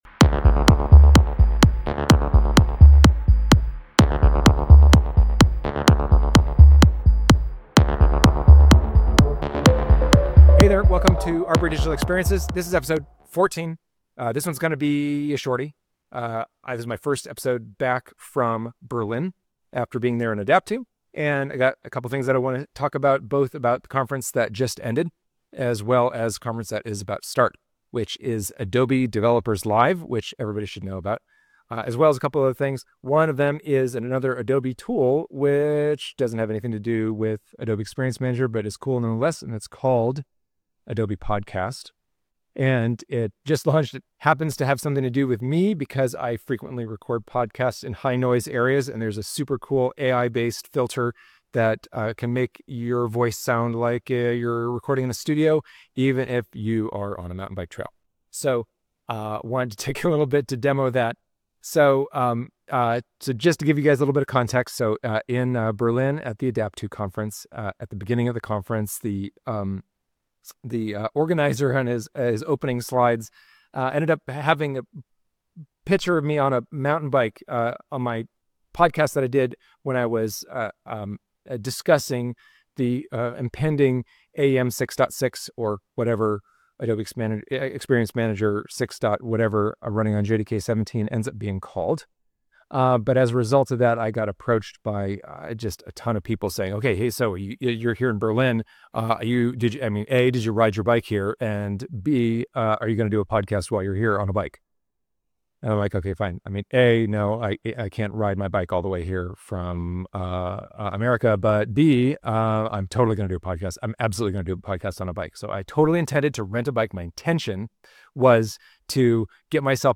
A combined Podcast and “Update from the Forest” with a first use of the new Adobe Podcast v2 AI Audio Enhancer.